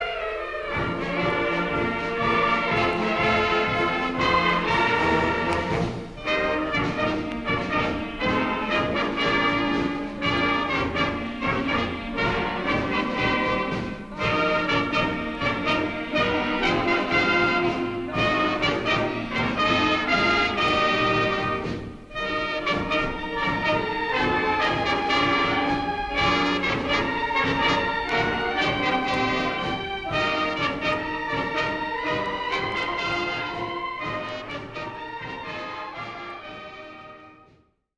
School Band Concert April 22, 1961
Recording of Performance by Two New York State Public School Bands April 22, 1961 [160kbps]
You can hear the children despite all the formality of the performance.